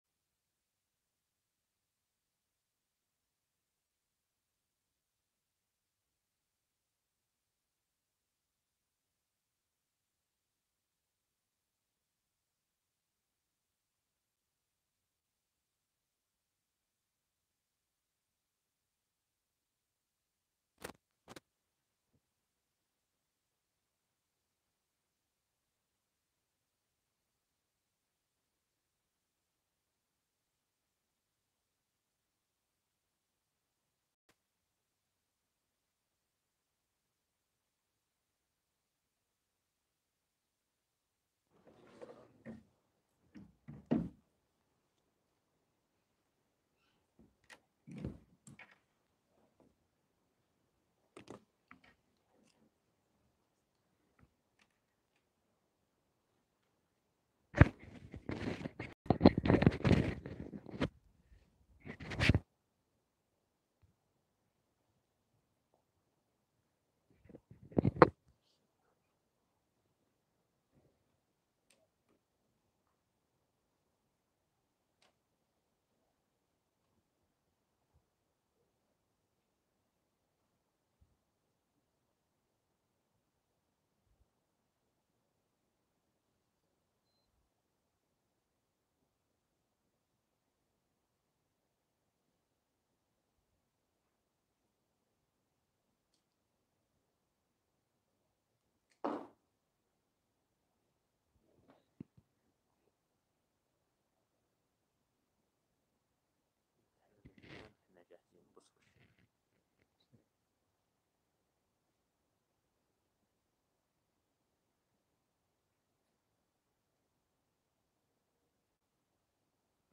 الموقع الرسمي لفضيلة الشيخ الدكتور سعد بن ناصر الشثرى | مسائل في تخريج الفروع على الأصول- الدرس (6)